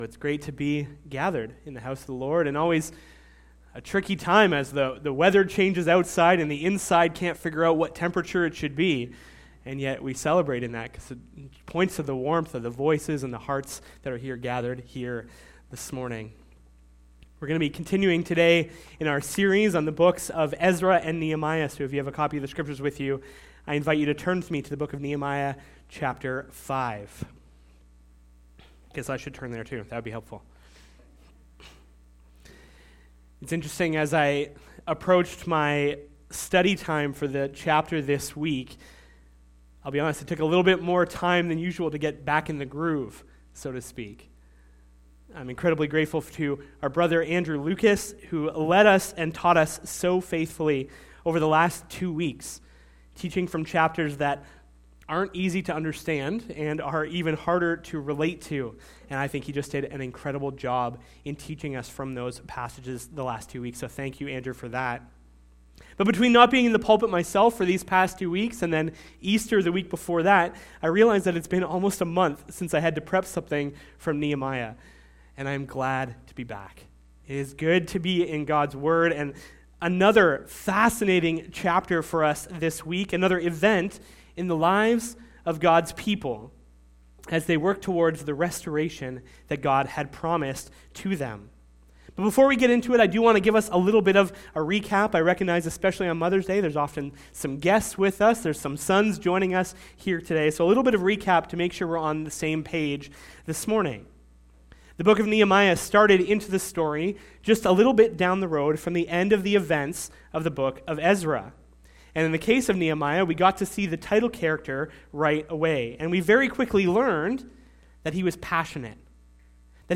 SERMONS - Community Bible Church